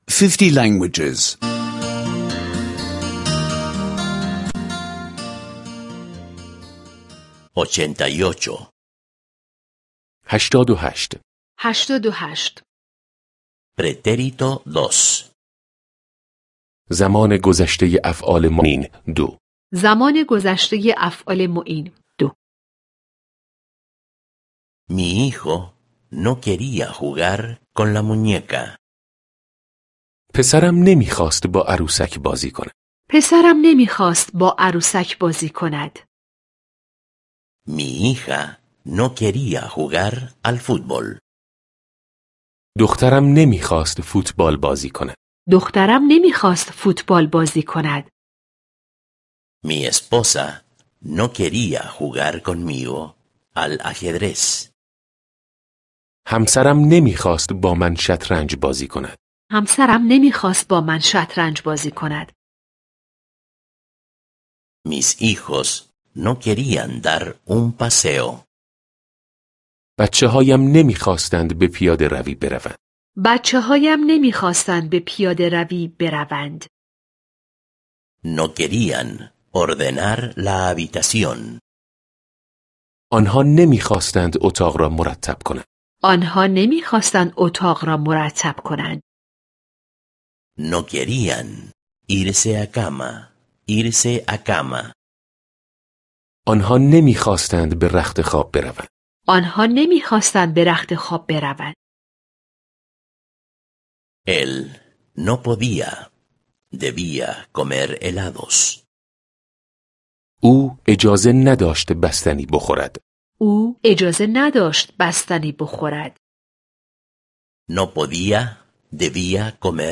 Curso de audio de persa (escuchar en línea)